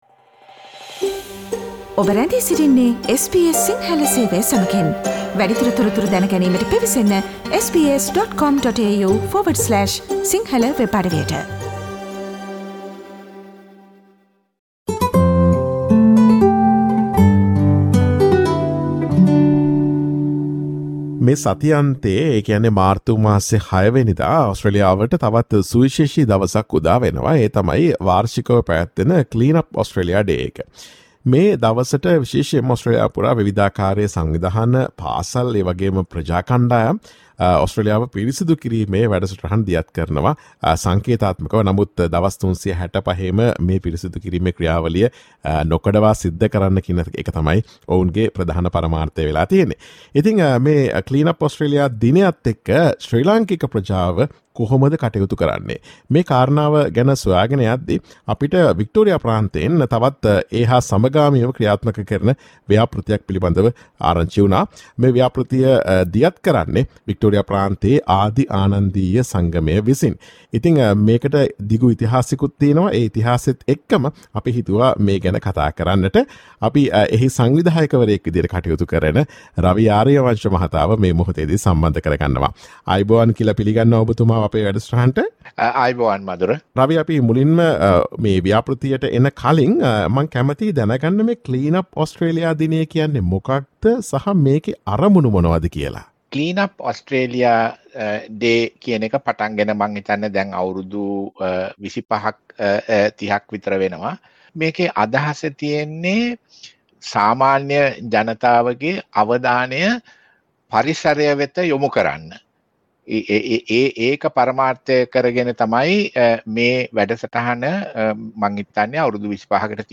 වික්ටෝරියා ප්‍රාන්තයේ ආදී ආනන්දීය සංගමය 2022 Clean Up Australia දිනයේදී සිදුකිරීමට සූදානම් වන පරිසර ව්‍යාපෘතිය පිළිබඳ SBS සිංහල ගුවන් විදුලිය සිදුකළ සාකච්ඡාවට සවන් දෙන්න.